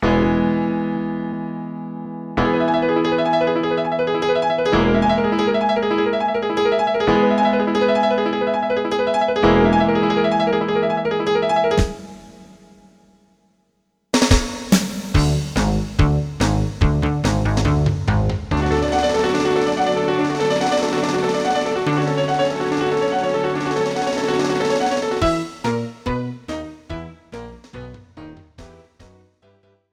This is an instrumental backing track cover.
• Key – E
• Without Backing Vocals
• No Fade